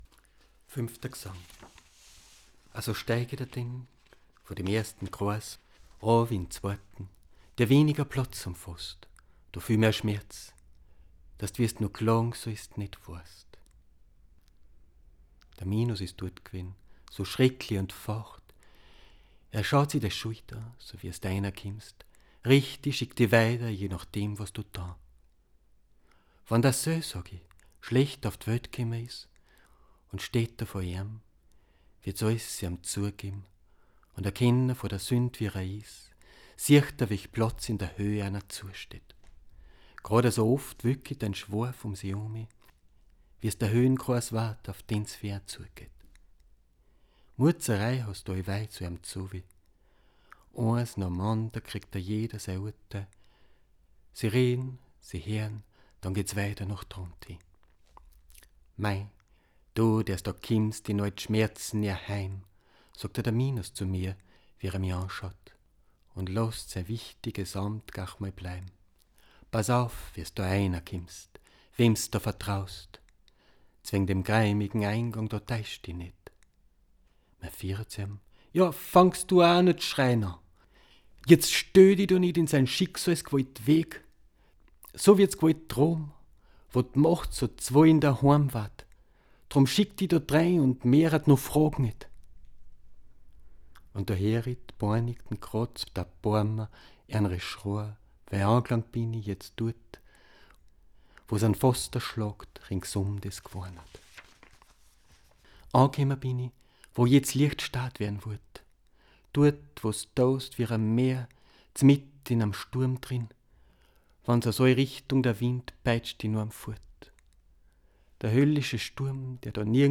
- ♫ Erste Audio-Skizze - (one-take recording - verleser ausgschnittn) ♫